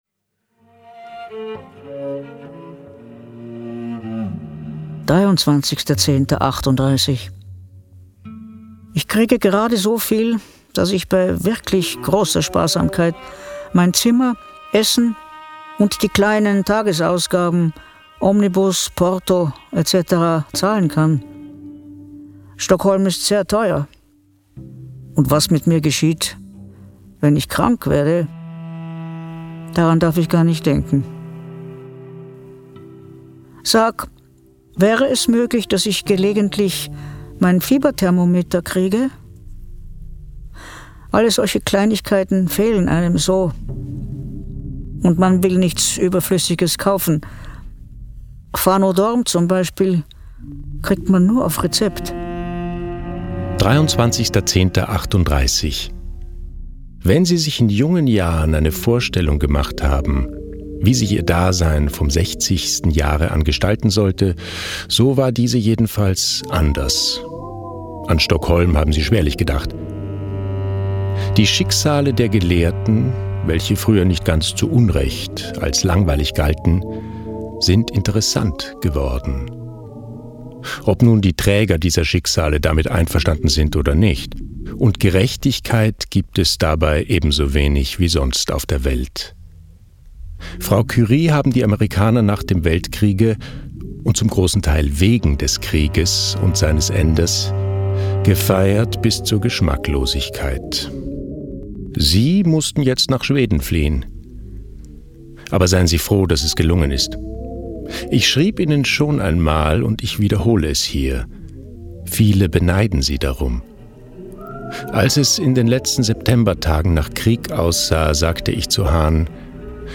Audiobuch mit Musik
Mitarbeit Sprecher: Elisabeth Orth